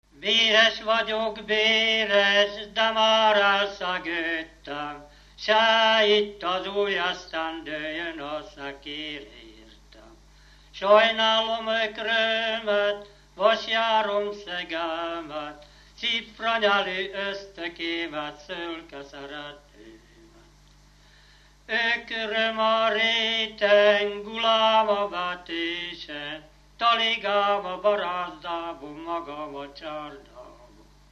Dunántúl - Veszprém vm. - Ősi
Stílus: 5. Rákóczi dallamkör és fríg környezete
Kadencia: 4 (1) 1 V